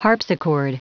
Prononciation du mot harpsichord en anglais (fichier audio)
Prononciation du mot : harpsichord